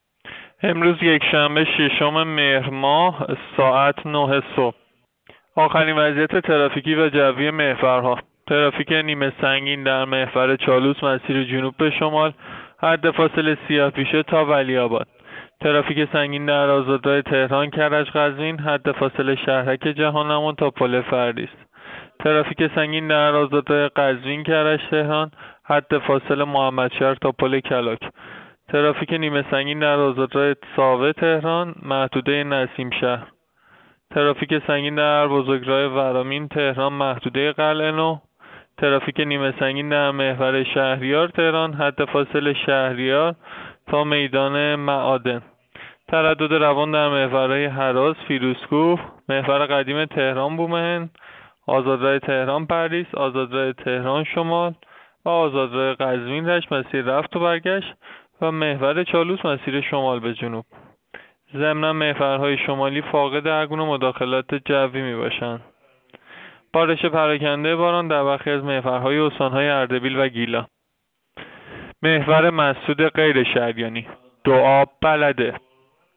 گزارش رادیو اینترنتی از آخرین وضعیت ترافیکی جاده‌ها ساعت ۹ ششم مهر؛